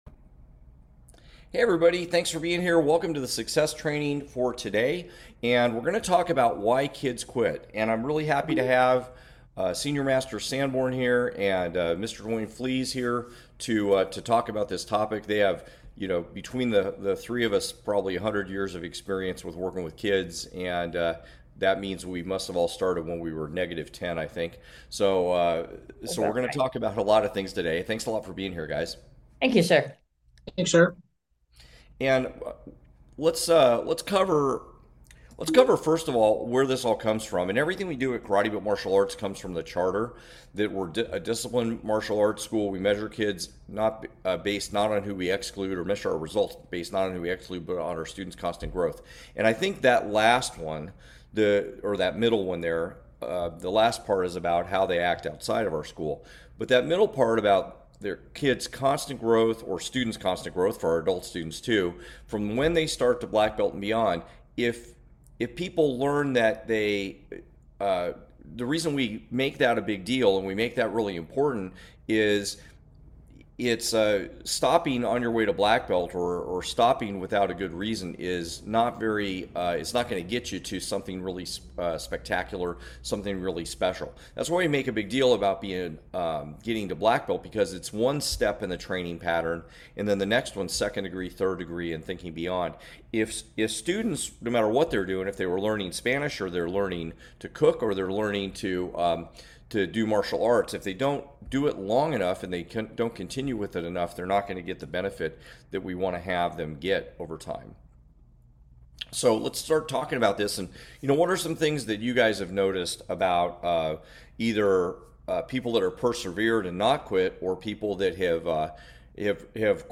You’ll hear how quitting usually happens right at the point where growth is about to occur—and why pushing through that moment is what builds confidence, discipline, and real strength. This episode explains the difference between activities that are simply fun and those that are critical for a child’s development, and why that distinction should guide every parenting decision. The conversation also covers how parents can respond when kids feel fear, anxiety, or resistance.